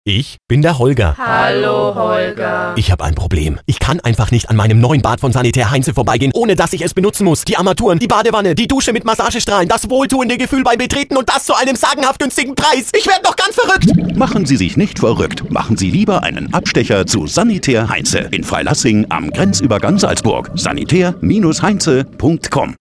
Werbestimme, Comedystimme, Radiowerbung, Fernsehwerbung, Radiomoderator uvm.
fränkisch
Sprechprobe: eLearning (Muttersprache):